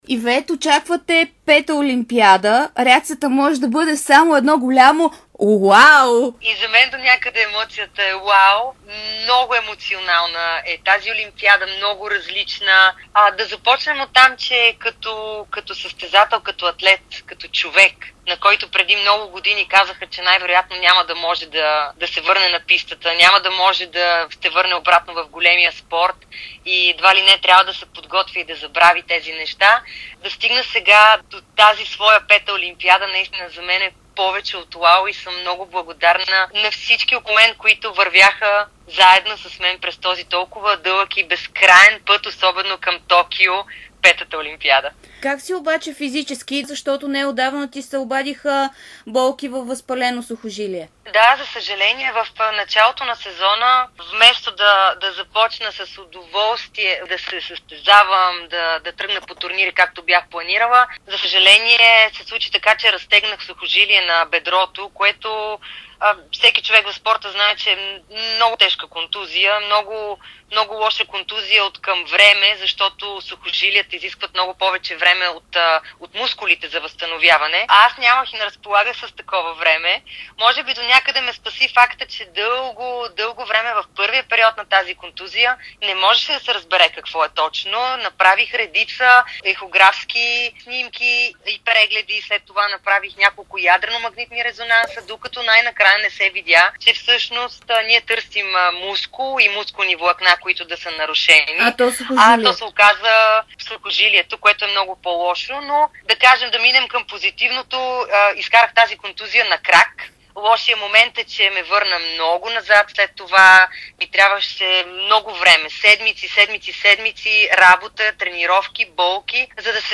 Ивет Лалова-Колио в петък заминава за Токио и своята много емоционална пета Олимпиада. Чаровната ни атлетка даде специално интервю за dsport и Дарик, в което сподели вълнението си.